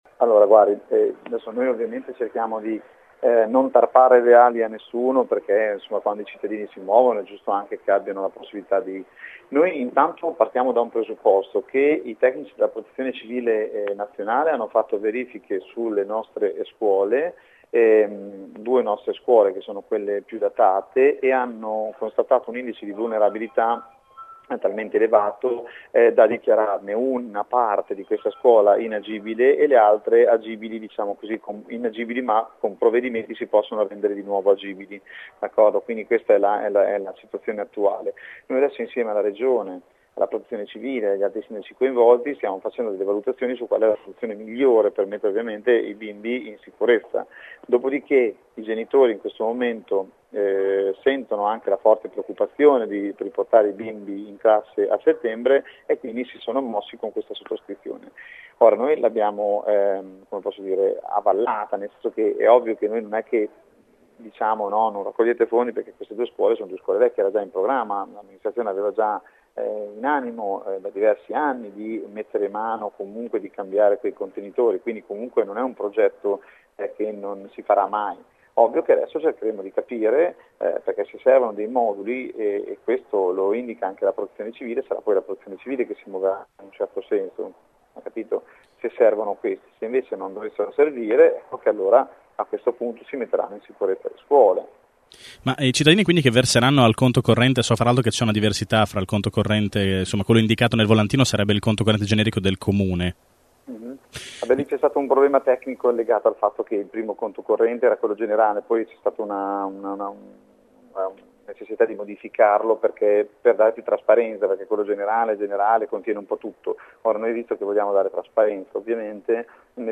Ascolta Mazzuca